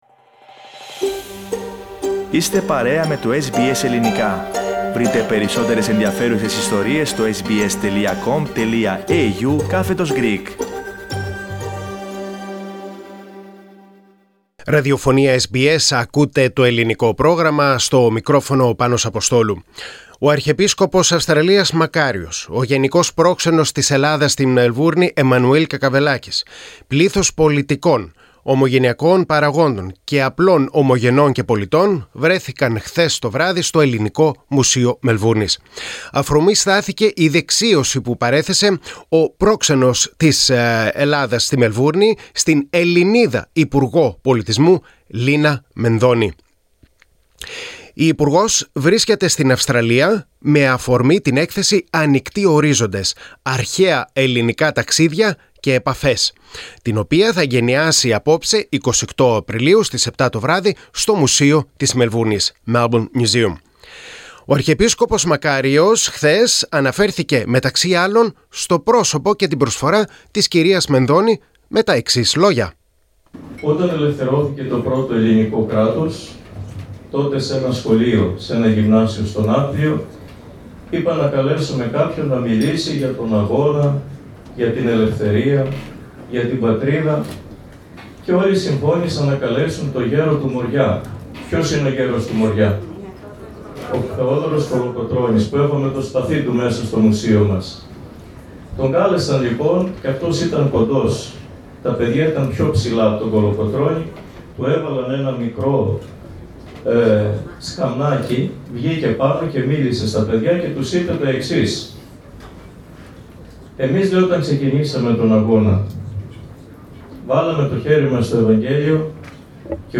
At the Hellenic Museum, Melbourne.